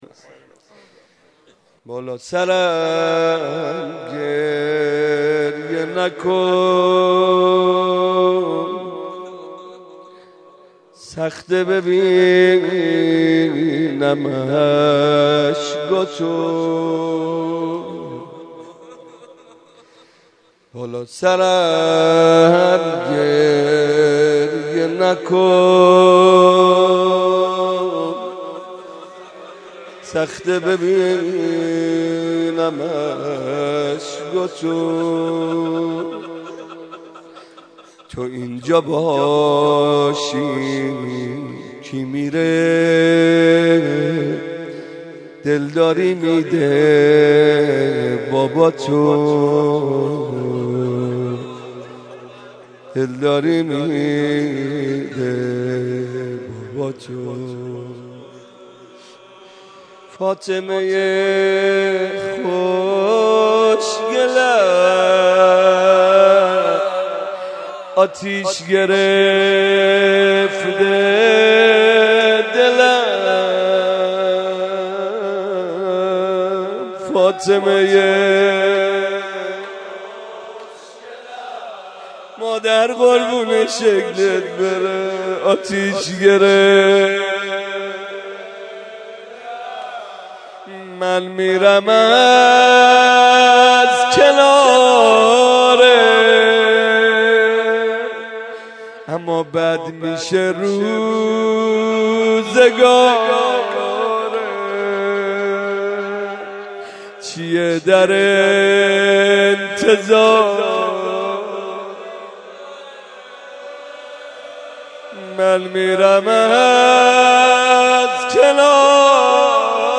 مناسبت : شب یازدهم رمضان
قالب : روضه